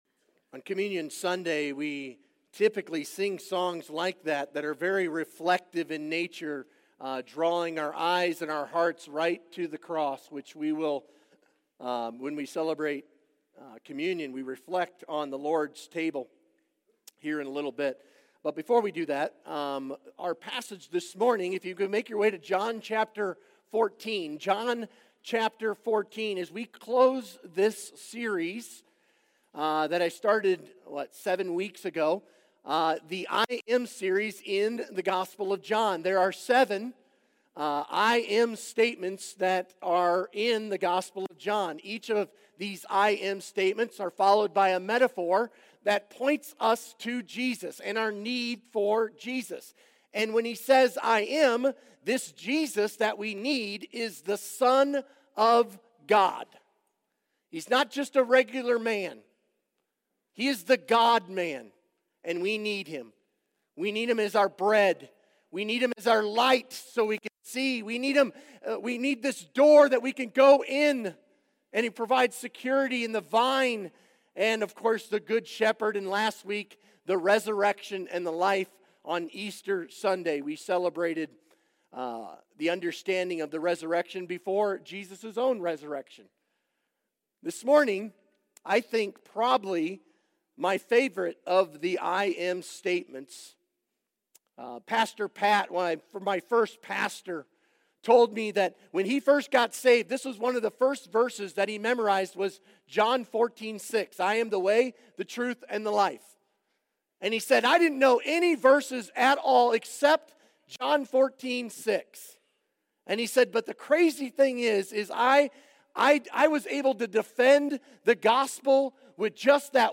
Sermon Questions Read John 14:1-6.